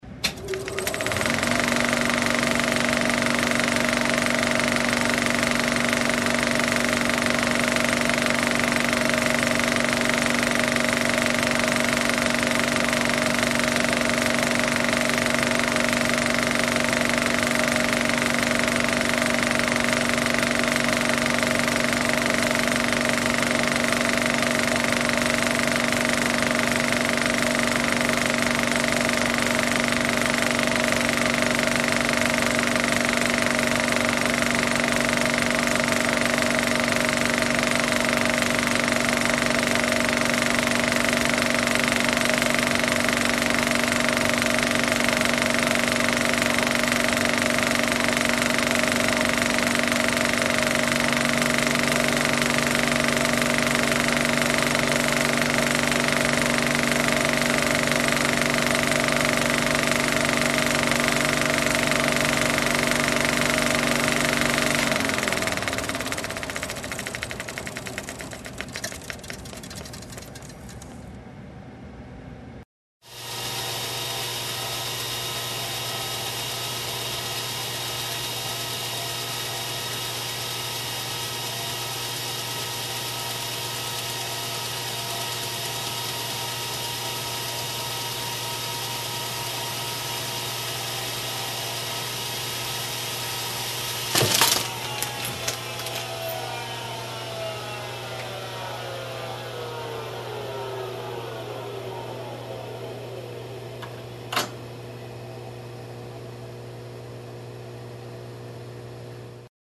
На этой странице собраны звуки кинопроектора в высоком качестве – от мягкого гула до характерных щелчков пленки.
Звук кинопроектора второй вариант